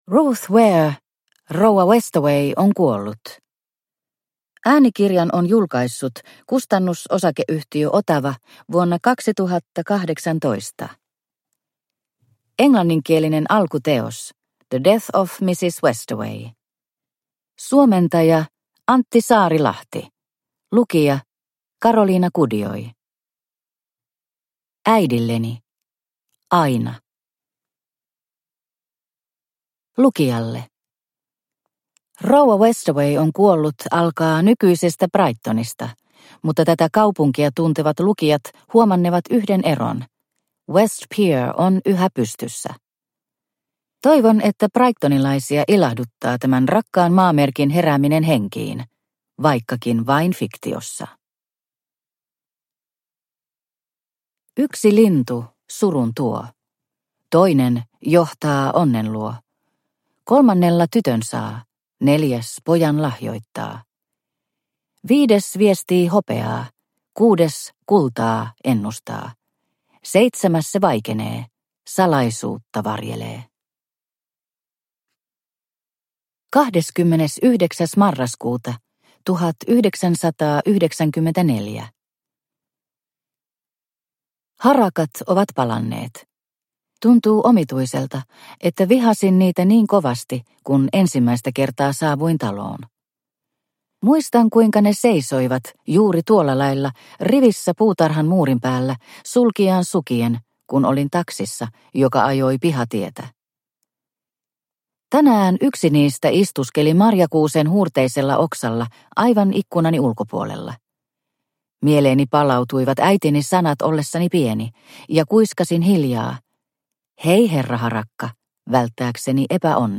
Rouva Westaway on kuollut – Ljudbok – Laddas ner